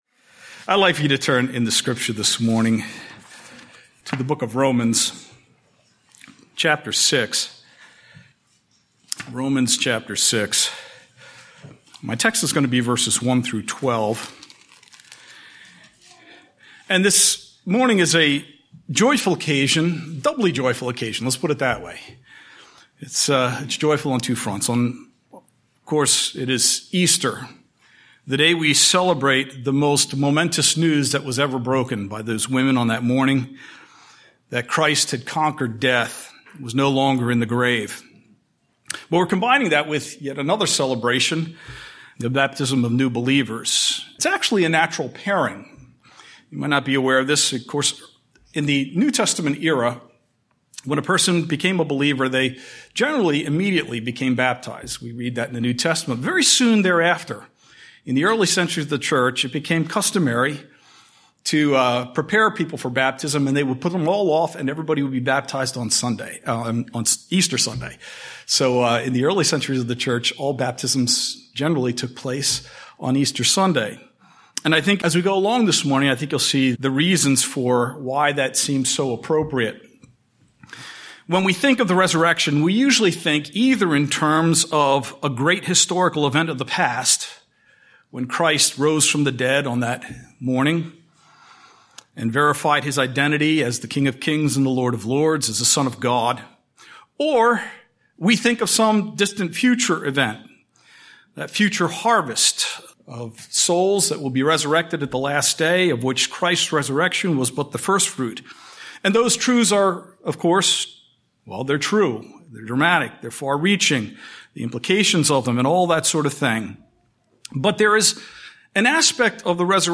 The Resurrection and Baptism . In the Easter sermon we look at the aspect of the Resurrection that is often overlooked and that is it’s implications for the present. It’s not just an event of historical significance but is it real power that has invaded the present, a power our lives are intended to bear witness to. 27:28 Palm Sunday